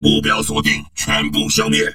文件 文件历史 文件用途 全域文件用途 Enjo_skill_05_2.ogg （Ogg Vorbis声音文件，长度2.0秒，115 kbps，文件大小：27 KB） 源地址:地下城与勇士游戏语音 文件历史 点击某个日期/时间查看对应时刻的文件。